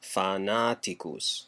Ääntäminen
Ääntäminen US : IPA : [ˈfrɛn.zid]